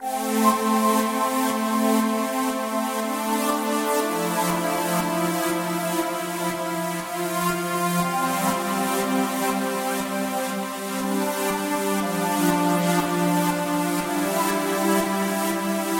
美味的舞蹈垫
描述：我把这些垫子保持在相当"尖锐"的声音，以便你可以随意软化它们，而不是自己添加过滤器...这是一个成功的和弦序列...下载，享受，并让我知道你是否用它做了什么。
标签： 120 bpm Trance Loops Pad Loops 2.69 MB wav Key : Unknown
声道立体声